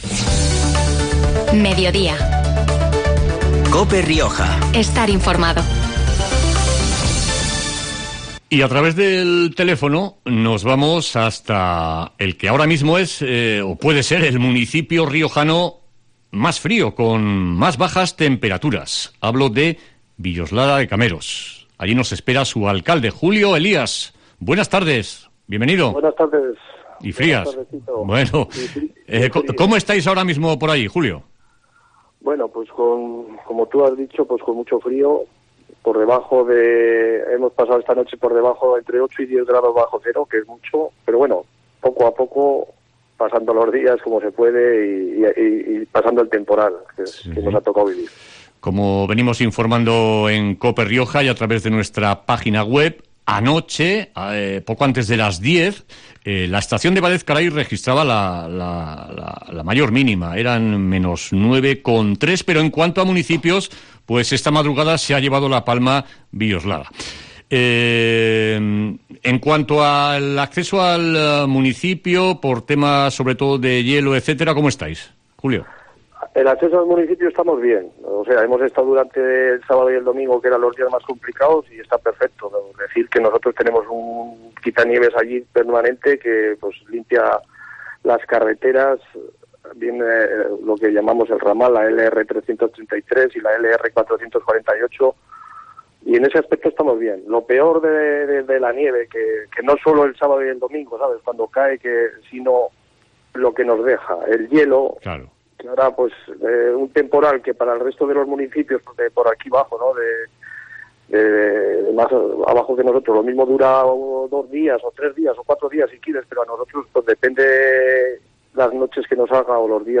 Entrevista en COPE Rioja al alcalde de Villoslada, Julio Elías